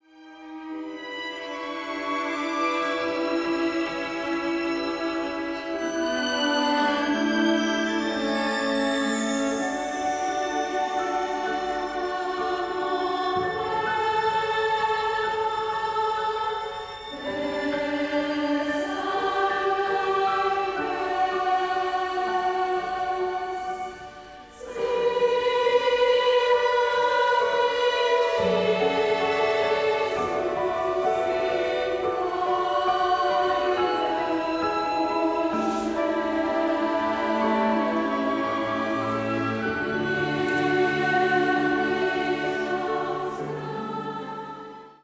• kurzweilige Zusammenstellung verschiedener Live-Aufnahmen